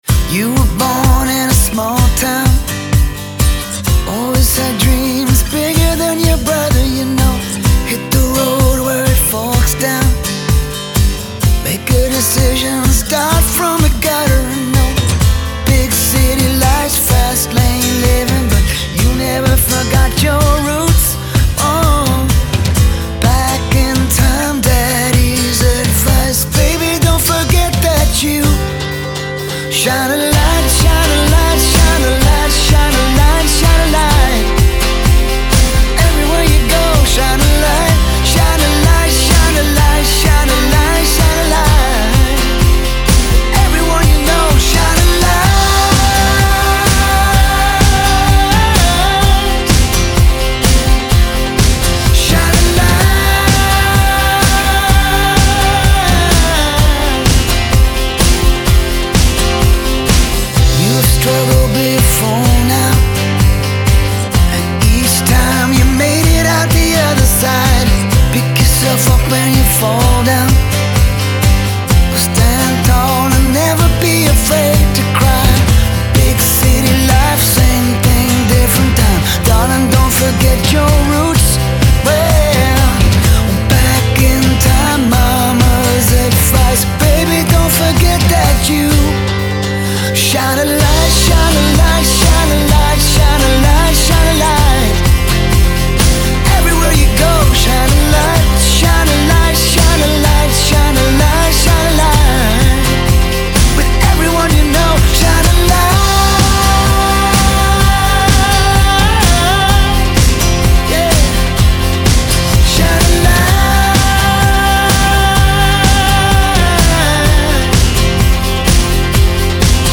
Rock, Pop Rock